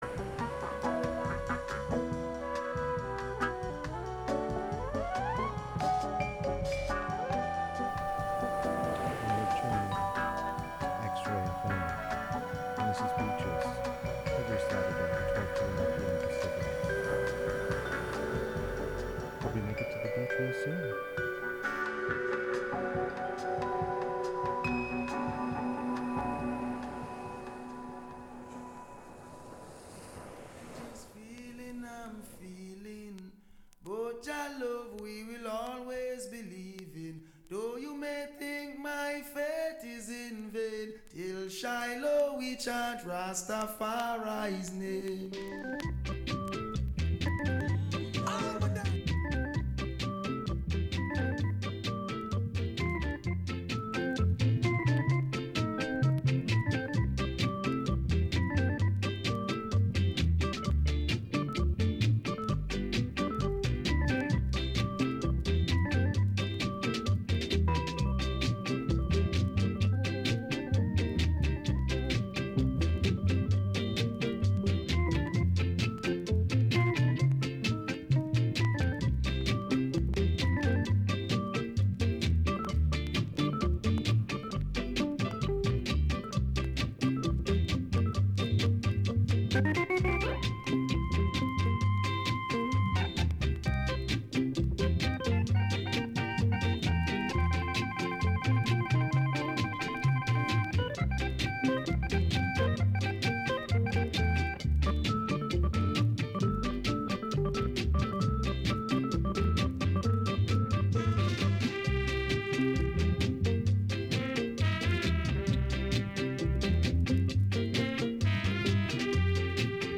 Sweet ska and rocksteady from the sixties, the deepest roots reggae from the seventies and the best dancehall from the eighties and beyond.